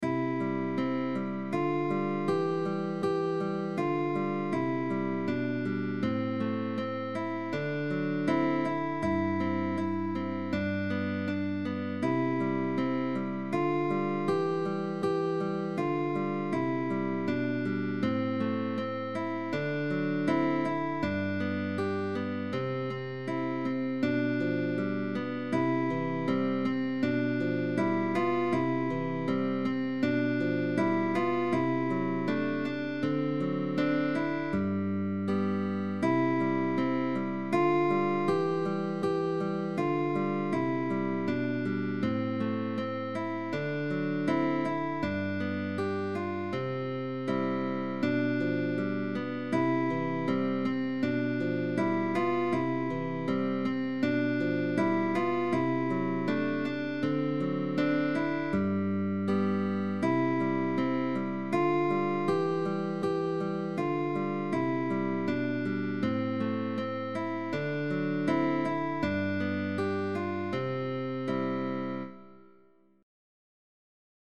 Difficulty: Very Easy